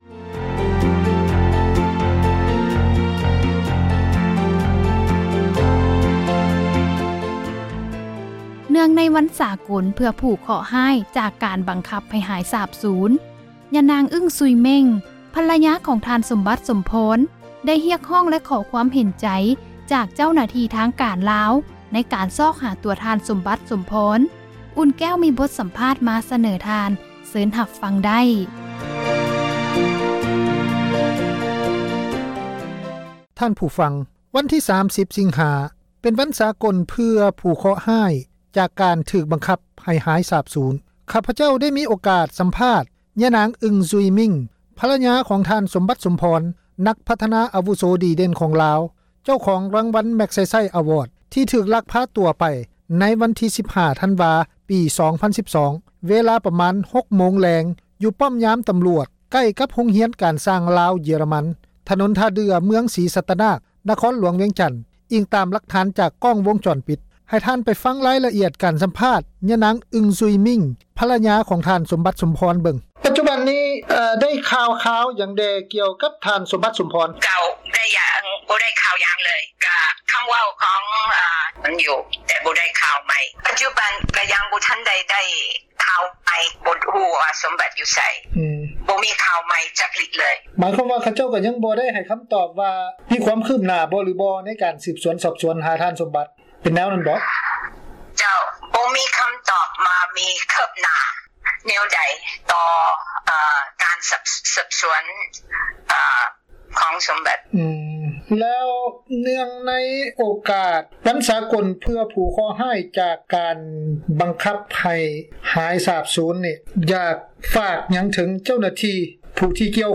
ທີ່ຖືກລັກພາຕົວໄປ ໃນວັນທີ 15 ທັນວາ ປີ 2012 ເວລາ ປະມານ 6 ໂມງແລງ ຢູ່ປ້ອມຍາມ ຕຳຣວດ ໃກ້ກັບໂຮງຮຽນ ການຊ່າງ ລາວ-ເຢັຽຣະມັນ ຖະໜົນທ່າເດື່ອ ເມືອງສີສັດຕະນາກ ນະຄອນຫລວງວຽງຈັນ ອີງຕາມຫລັກຖານ ຈາກ ກ້ອງວົງຈອນປິດ. ເຊີນທ່ານ ຟັງ ບົດສຳພາດ: